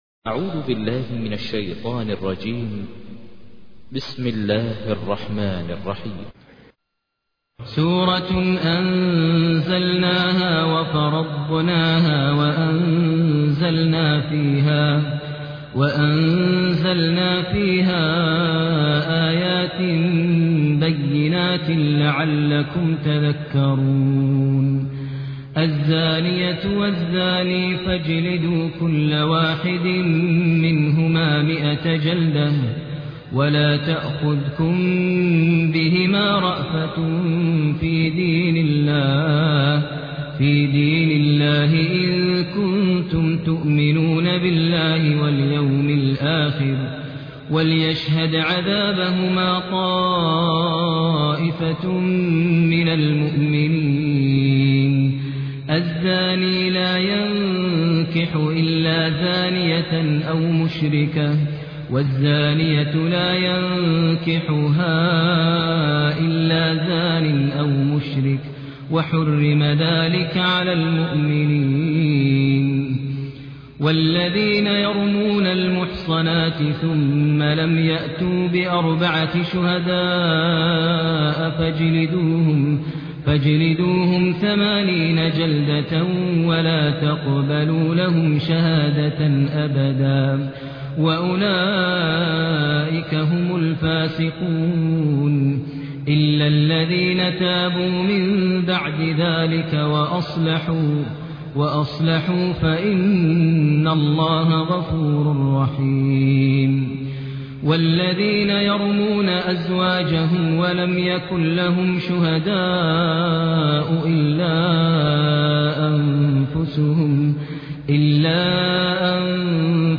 تحميل : 24. سورة النور / القارئ ماهر المعيقلي / القرآن الكريم / موقع يا حسين